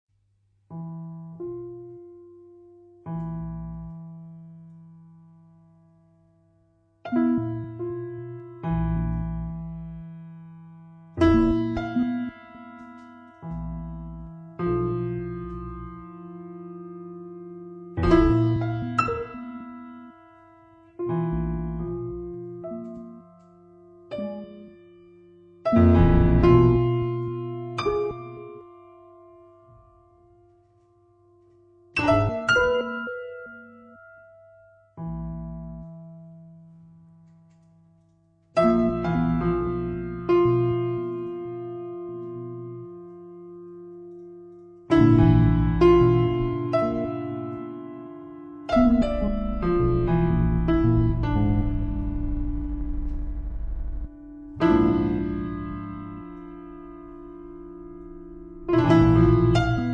pianoforte, oggetti
live electronics
Un'esecuzione inconsueta per un organico atipico
L'intero album è stato registrato dal vivo